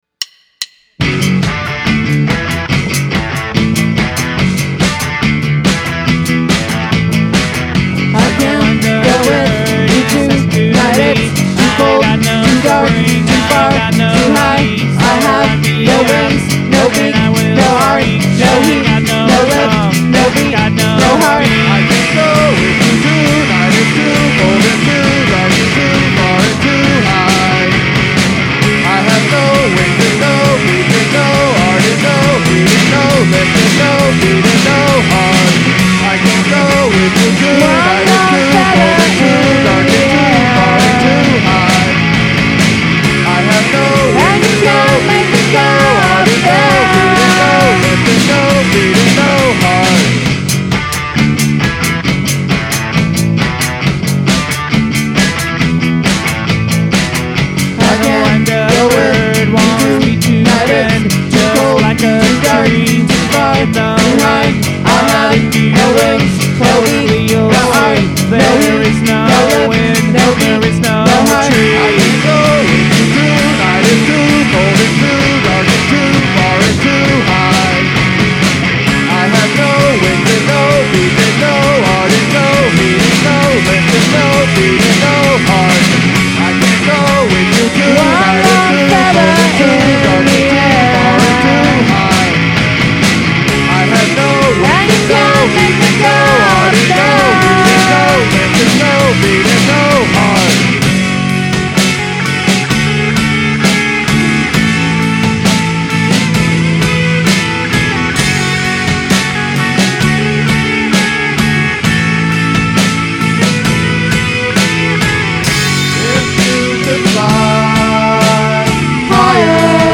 rhythm guitar